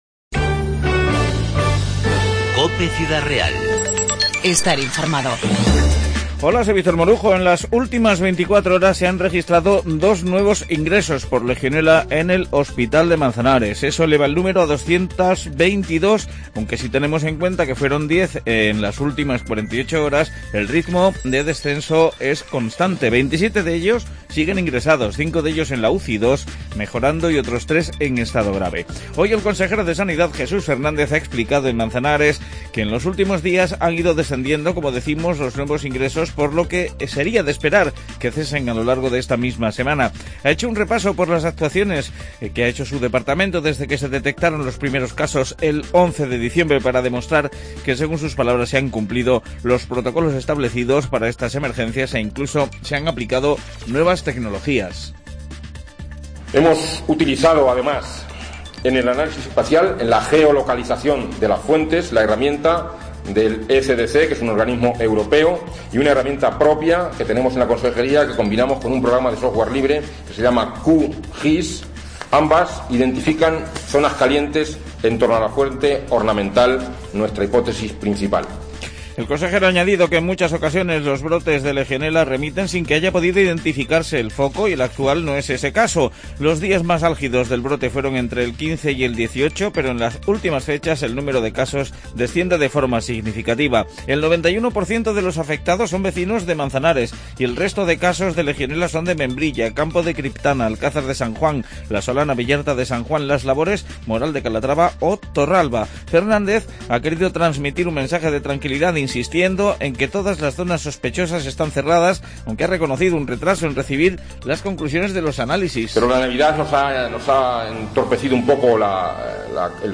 INFORMATIVO 28-12-15